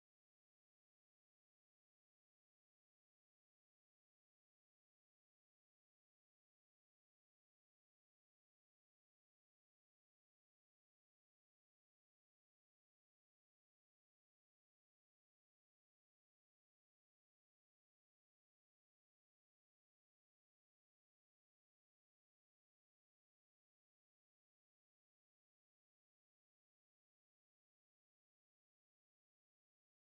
1kHZTest.mp3